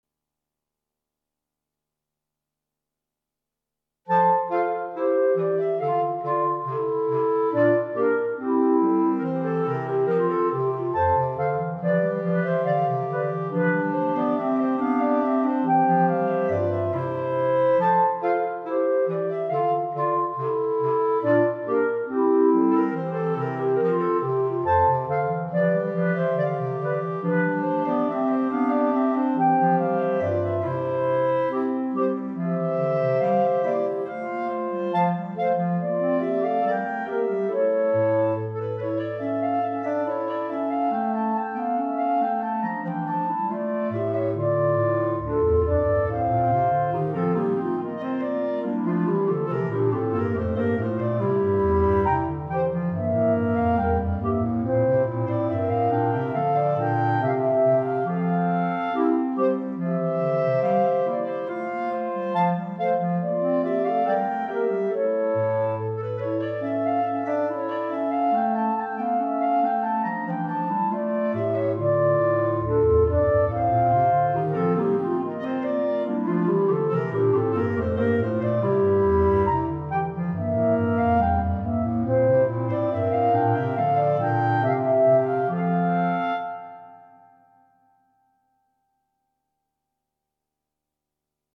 Instrumentation:Clarinet Quartet (includes Bass Cl)